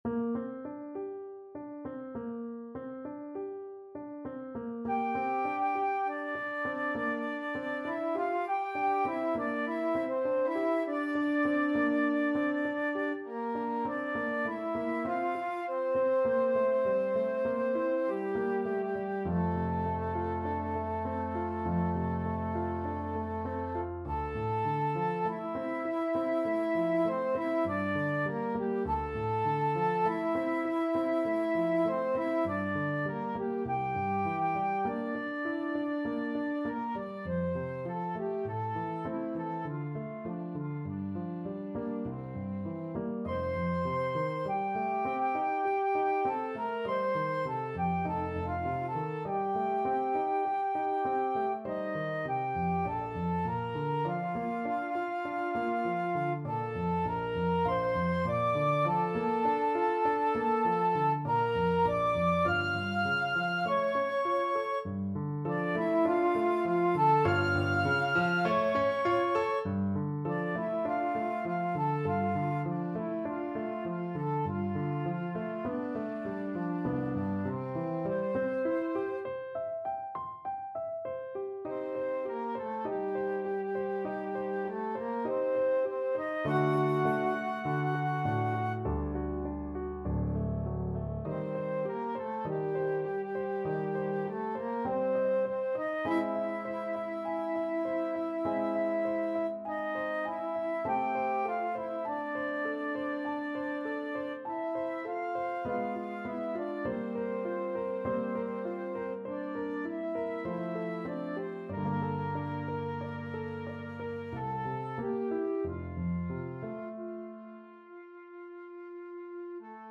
flute and piano